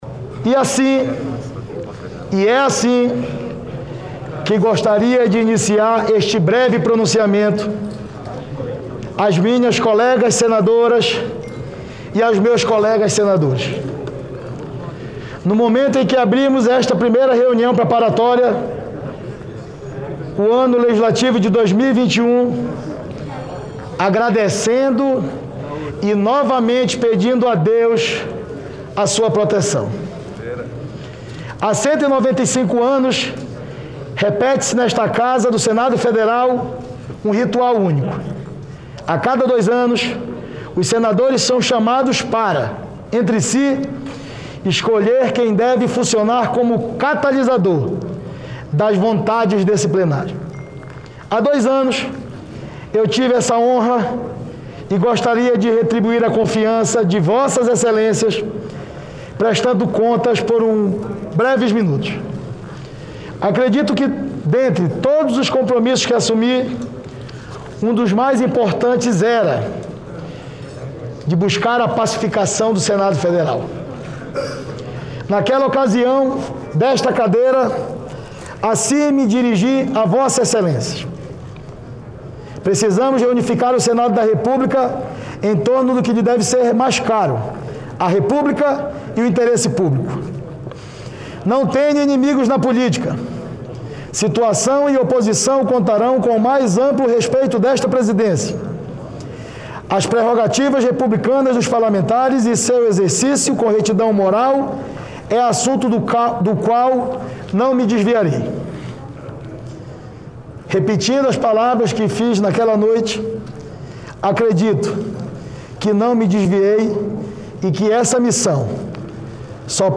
Ao abrir a sessão para eleição do novo presidente do Senado, Davi Alcolumbre fez nesta segunda-feira (1º) um balanço da sua gestão. Ele disse que procurou ser um nome de consenso durante as crises, evitando o confronto entre as instituições.
O senador também ressaltou a produtividade legislativa em um ano de pandemia. Ouça o áudio com o pronunciamento de Davi Alcolumbre.